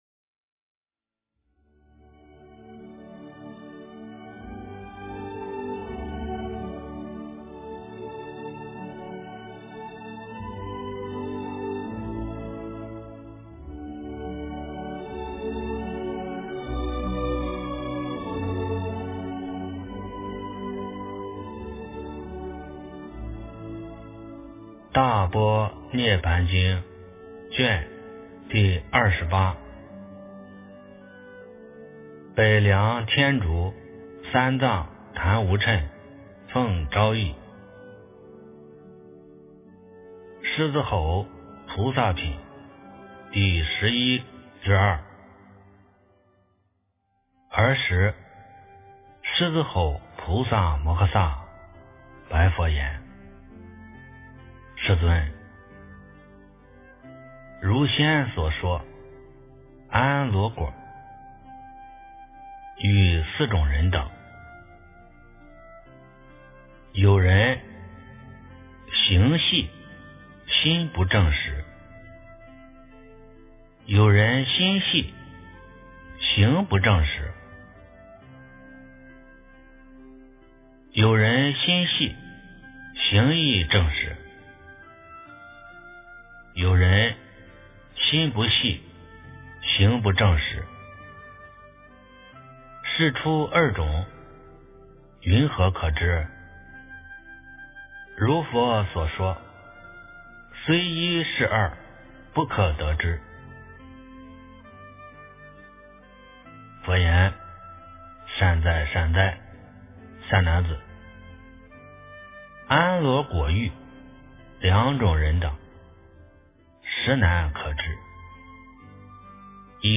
大般涅槃经28 - 诵经 - 云佛论坛